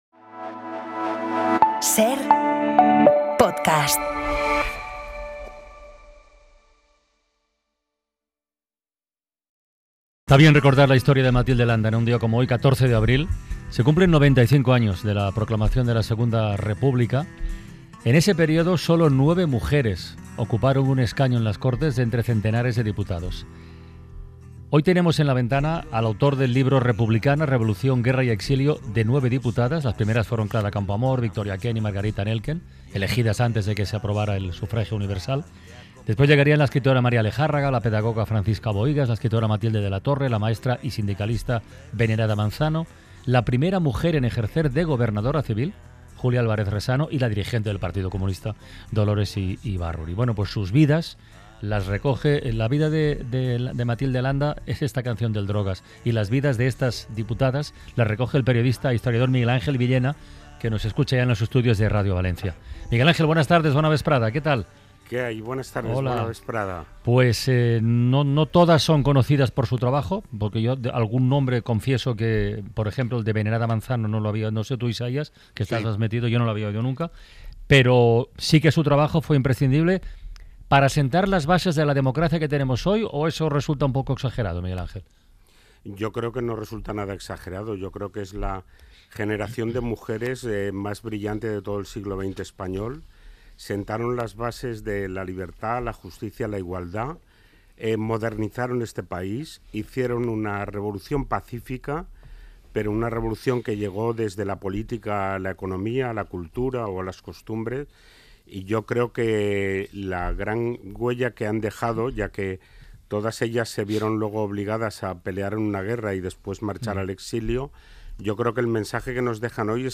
La Ventana | Entrevista